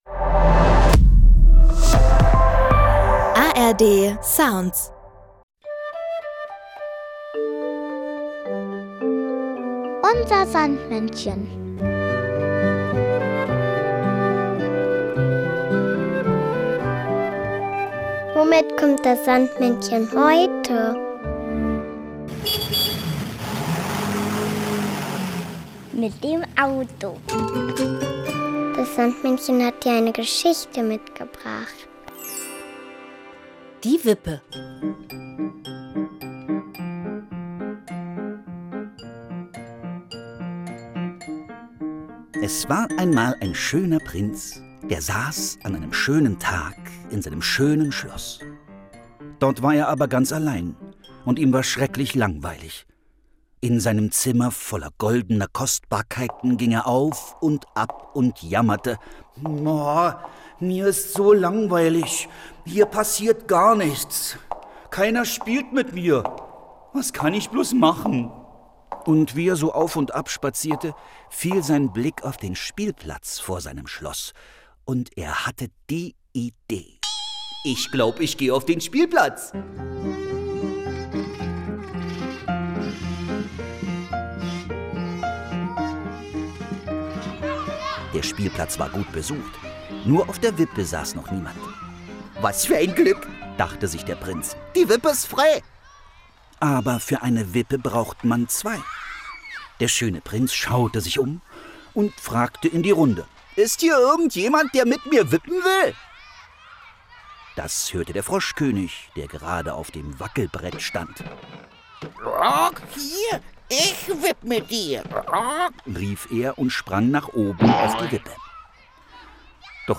Unser Sandmännchen: Geschichten und Lieder 13 ~ Unser Sandmännchen Podcast